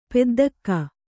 ♪ peddakka